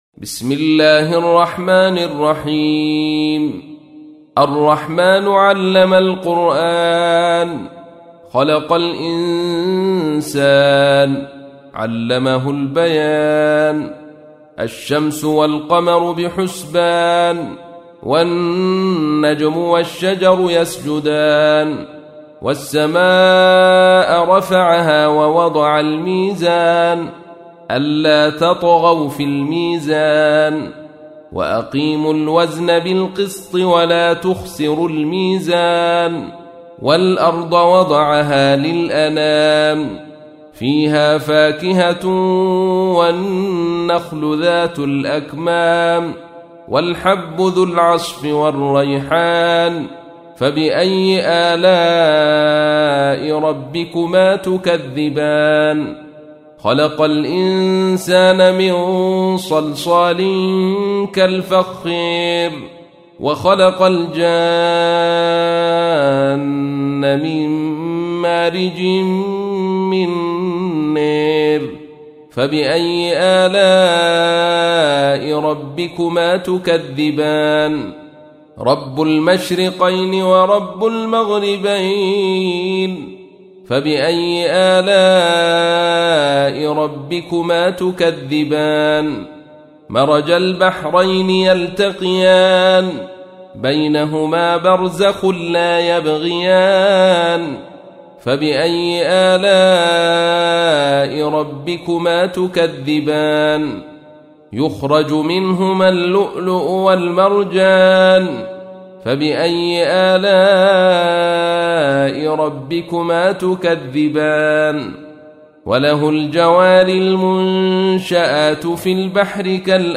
تحميل : 55. سورة الرحمن / القارئ عبد الرشيد صوفي / القرآن الكريم / موقع يا حسين